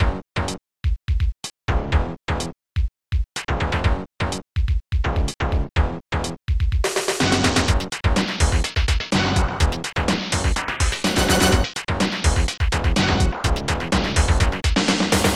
Protracker Module
Tracker Noisetracker M.K.